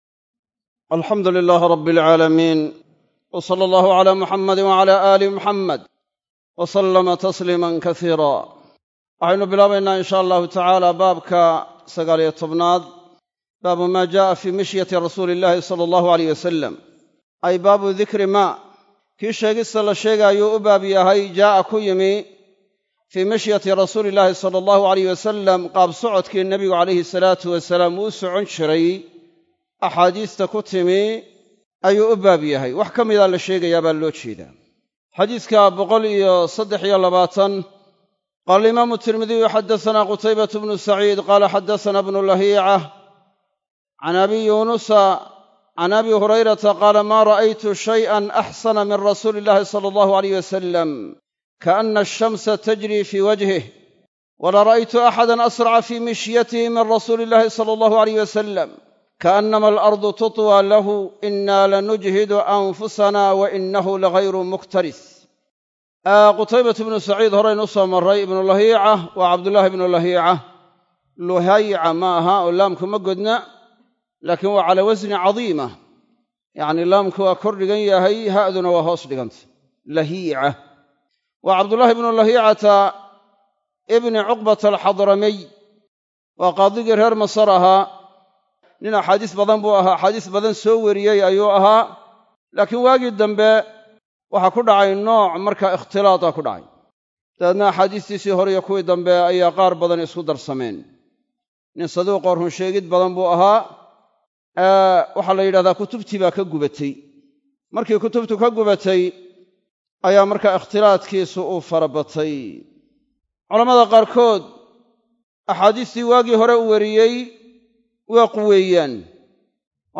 Masjid Af-Gooye – Burco